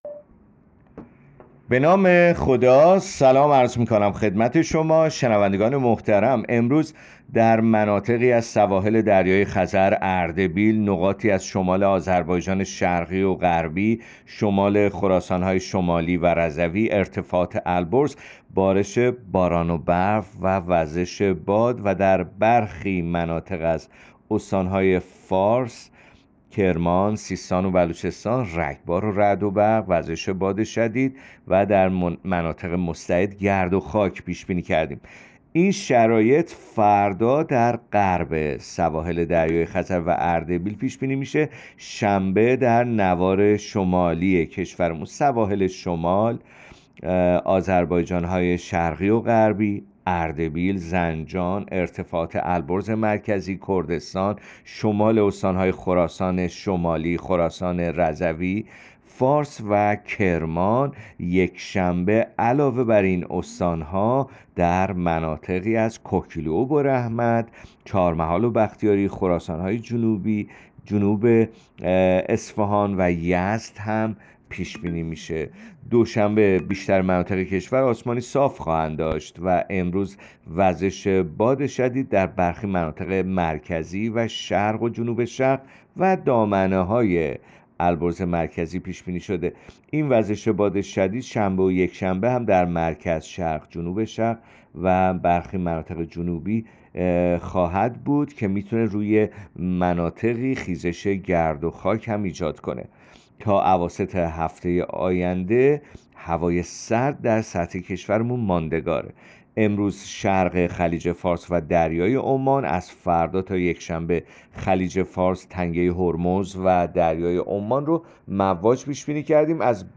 گزارش رادیو اینترنتی پایگاه‌ خبری از آخرین وضعیت آب‌وهوای ۹ اسفند؛